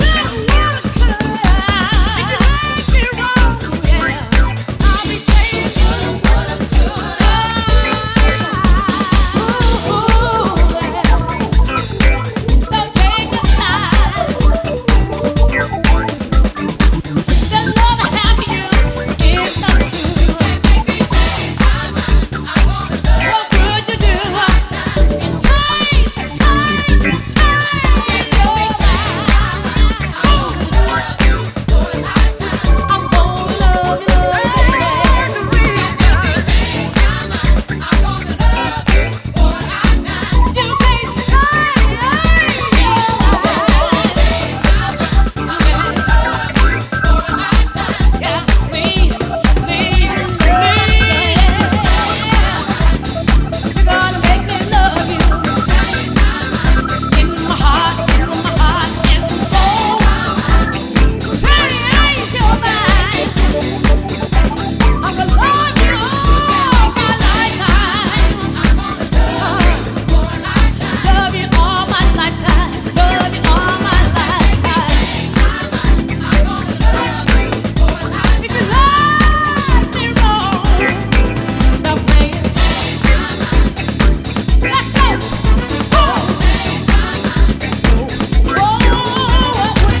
原曲のソウル/ゴスペル感を存分に活かしたエモーショナルなヴォーカル・ハウスに仕上がっています。
ジャンル(スタイル) DEEP HOUSE / SOULFUL HOUSE / HOUSE